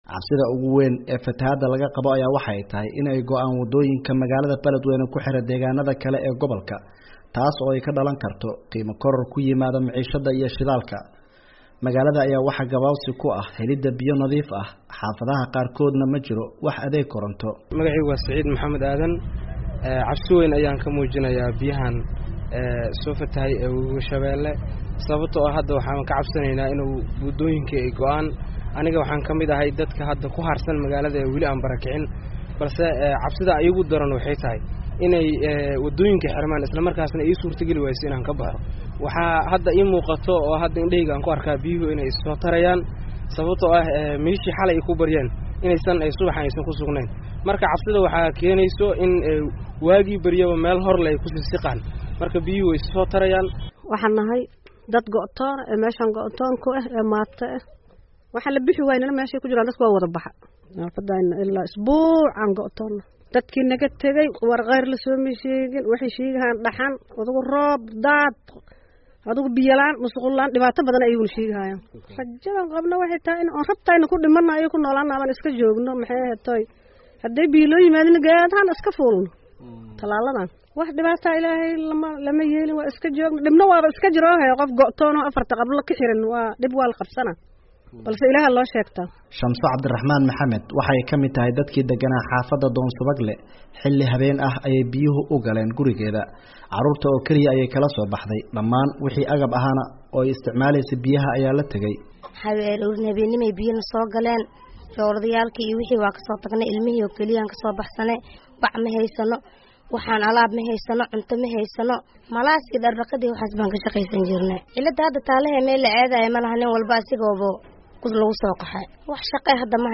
ayaa booqday qaar ka mid ah dadka ay daadadku saameeyeen warbixintan ayuuna nooga soo diray.